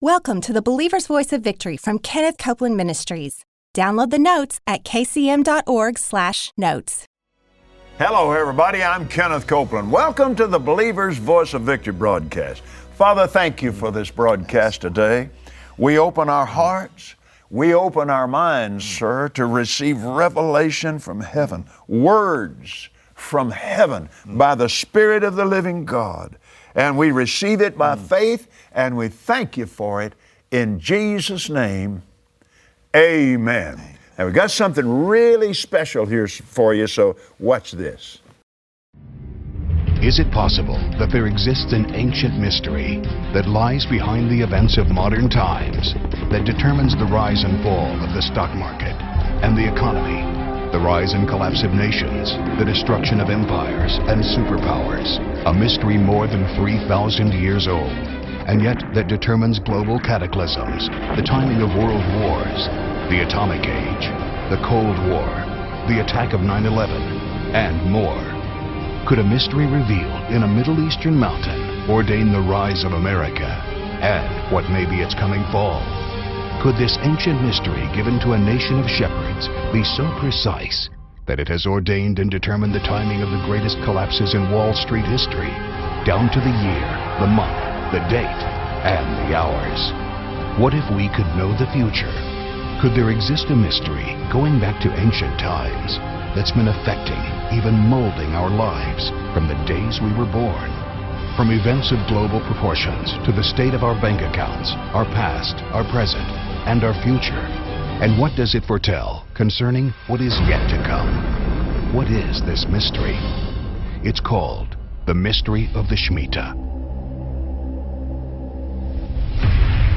Believers Voice of Victory Audio Broadcast for Tuesday 02/02/2016Today, on the Believer’s Voice of Victory, Kenneth Copeland and his guest, Rabbi Jonathan Cahn, reveal how the “Shemitah” has influenced world history and is orchestrating end-time events, demonstrating the power of God worldwide.